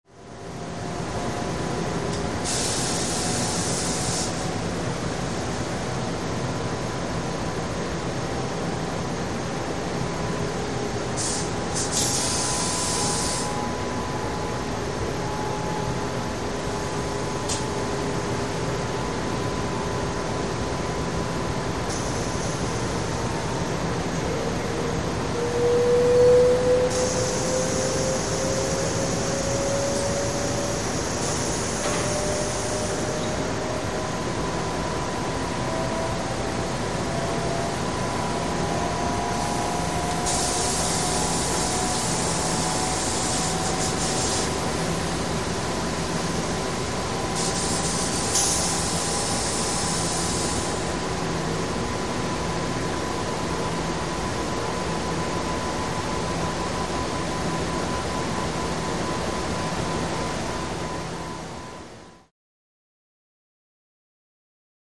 Screech, Fan | Sneak On The Lot
Steady Machine Hum And Whirring With Steam Blasts In Reverberant Space